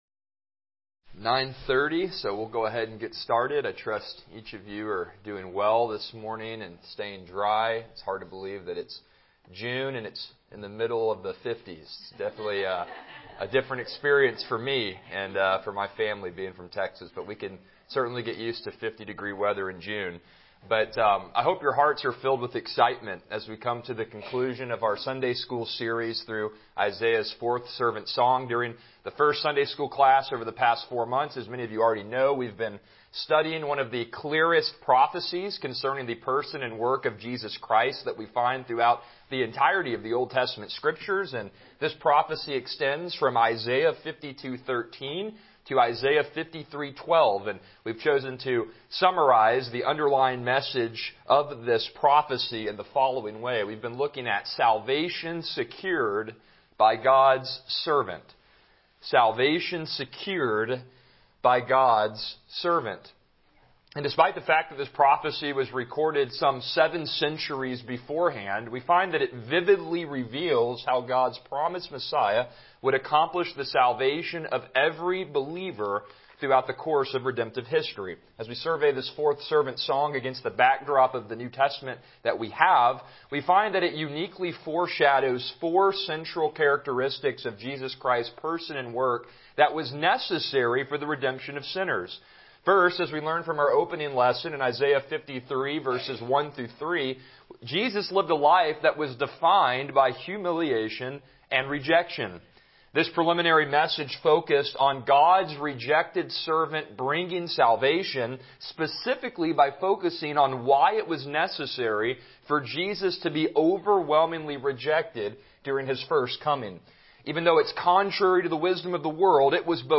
Passage: Isaiah 52:13-15 Service Type: Sunday School